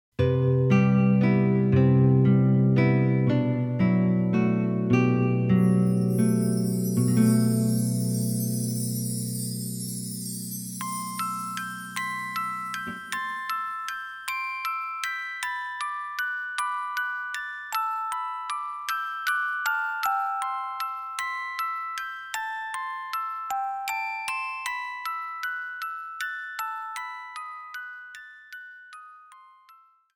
多種多様ながらも個性的な音楽が盛り沢山！！